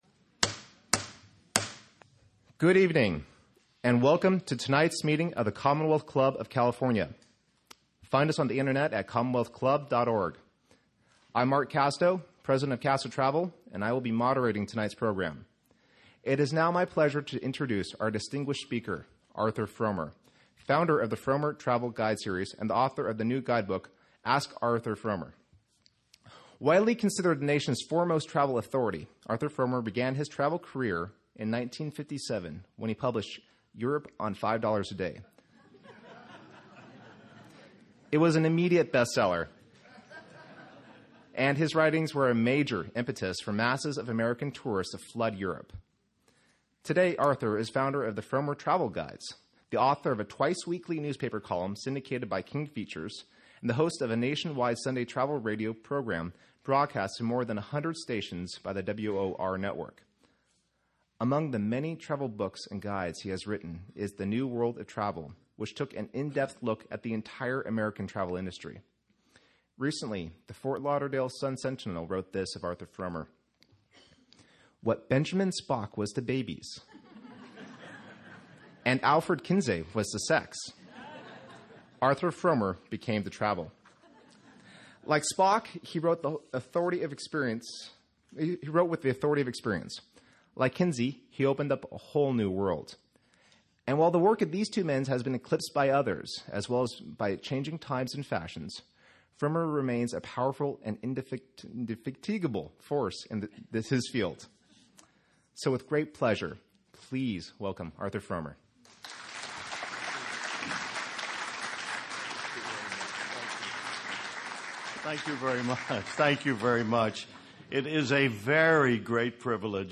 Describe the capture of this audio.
Learn the tricks of the trade and come find out how to travel on the cheap from the man who brought you Europe on $5 a Day. Location: The Veterans Memorial Hall, 3780 Mt Diablo Blvd., LafayetteTime: 5:45 p.m.